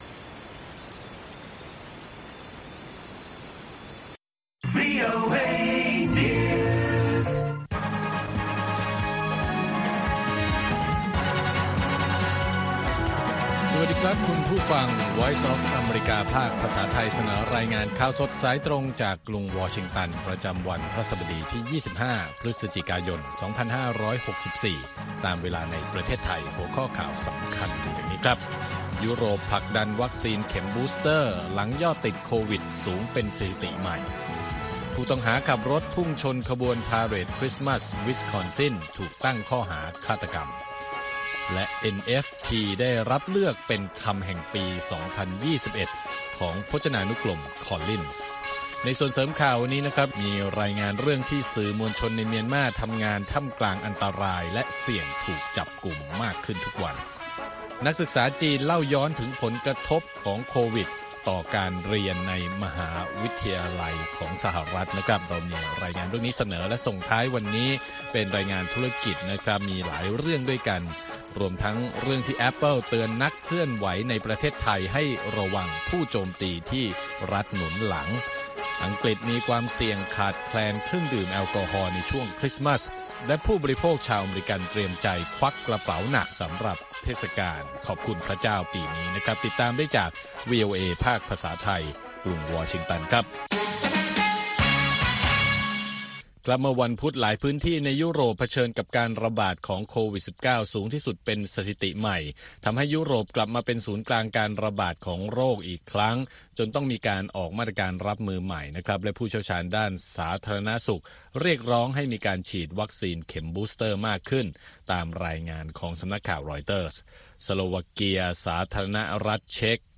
ข่าวสดสายตรงจากวีโอเอ ภาคภาษาไทย 8:30–9:00 น. ประจำวันพฤหัสบดีที่ 25 พฤศจิกายน 2564 ตามเวลาในประเทศไทย